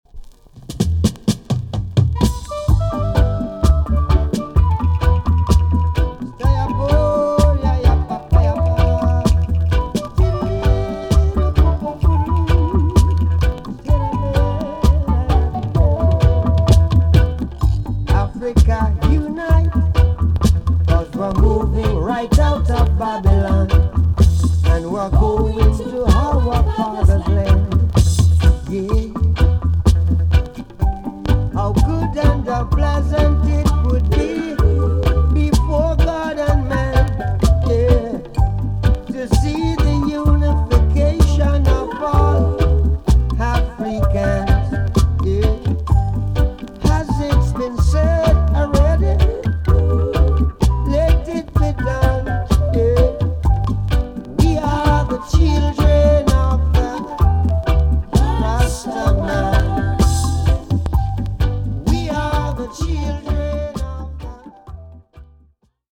A.SIDE EX-~VG+ 少しプチノイズの箇所がありますが音は良好です。